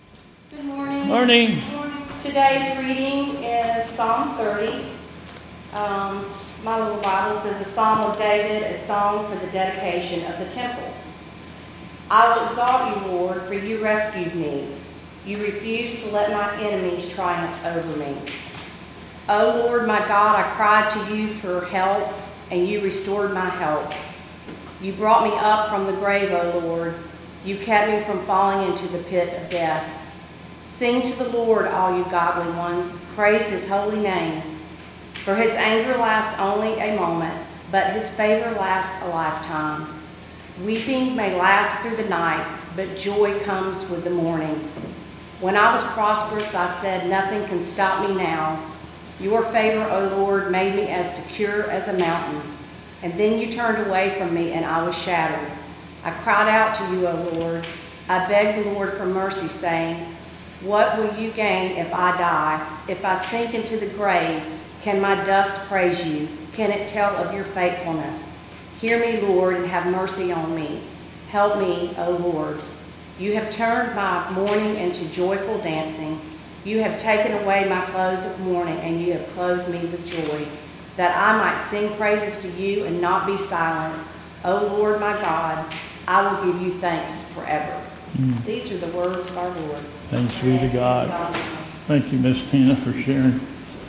2022 Bethel Covid Time Service
Offertory - Thanks for your support, service...and our freedoms..."
...prayer of thanksgiving....and...singing of the Doxology